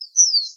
由JCG Musics于2015年创建的名为AmbiGen的装置中使用的单个鸟啁啾和短语。
Tag: 鸟鸣声 自然 现场录音